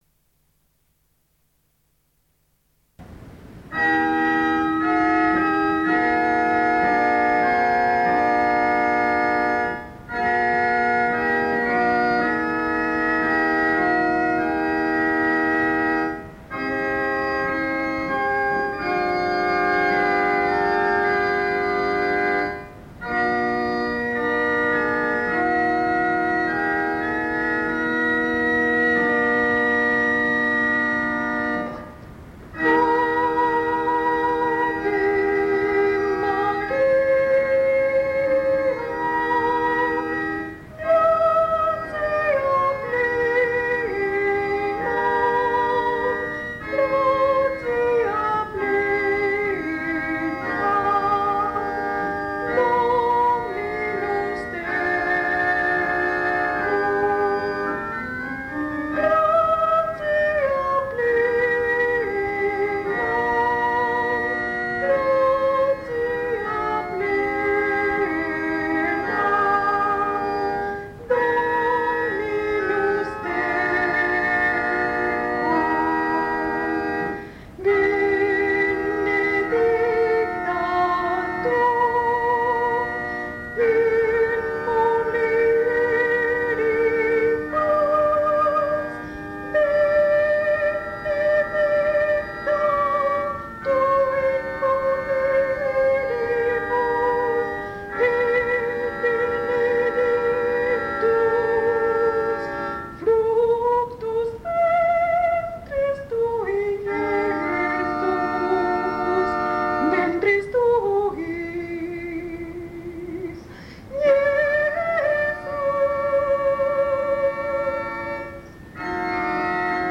Lieder: 13.- Ave María
órgano